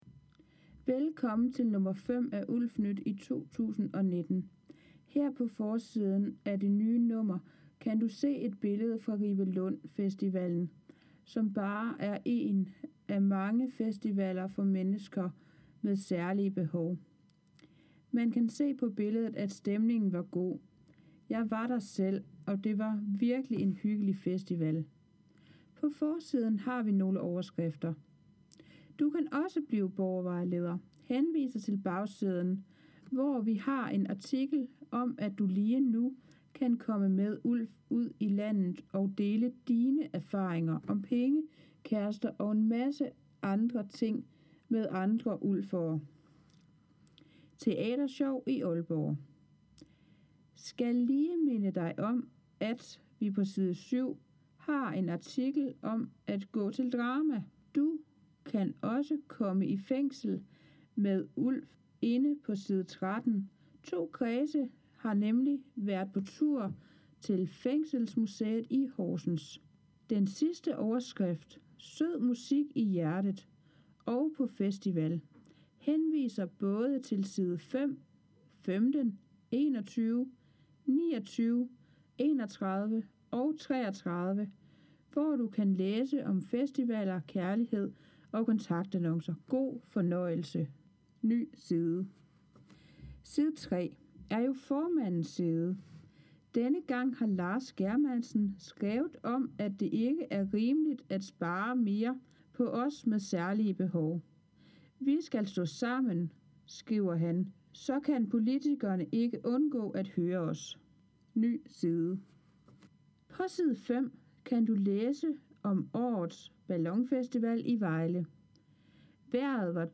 ULF nyt nr. 5 – 2019 – Uddrag speakes her: